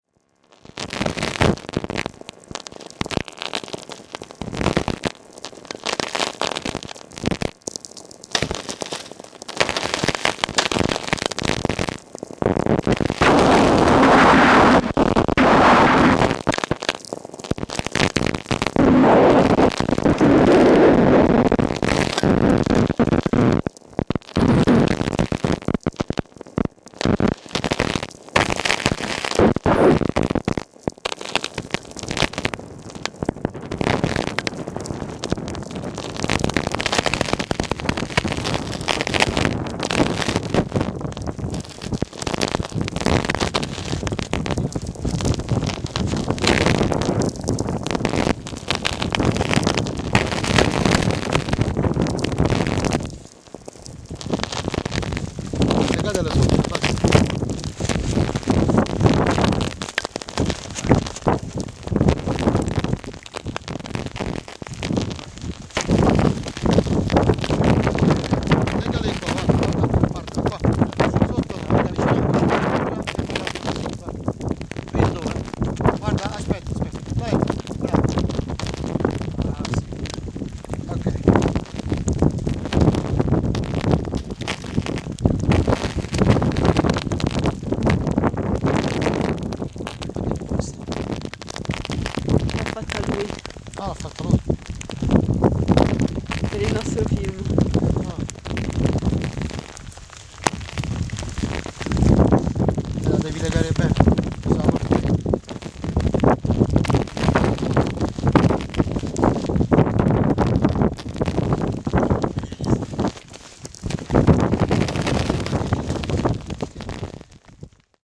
I slid across the leather seats as the car swung around the obstacles on the track; pulled forwards and pushed back as the steep slopes were tackled. The headlights carved tunnels of green from the forest, the keys rattled against themselves and the steering column, the windscreen wipers rubbed the glass in wide sweeps. Leveling out at the plateau on the summit, a sudden gift, offered then instantly withdrawn: a gaunt wolf’s yellow eyes in our beams. Hard cold gusts of rain-filled wind; the cloud ceiling below us flashed by lightning strikes as inverted trees; our torches on the shrine.
Field Recording Series by Gruenrekorder